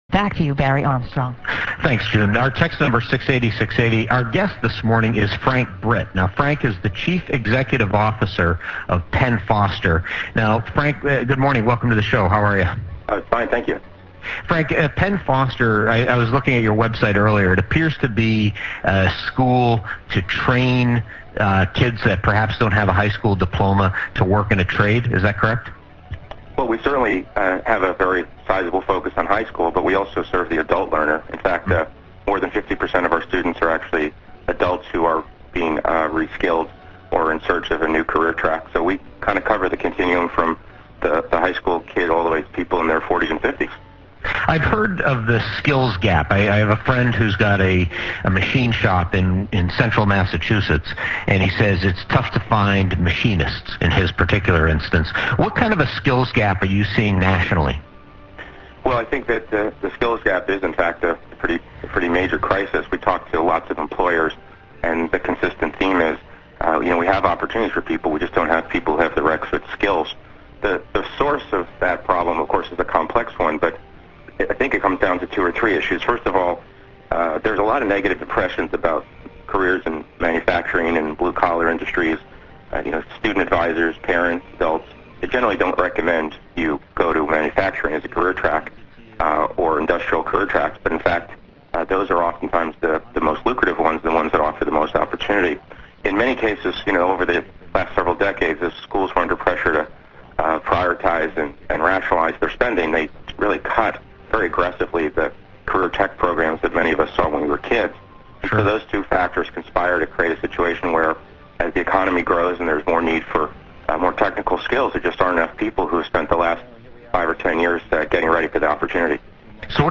WRKO Middle-skills Gap Interview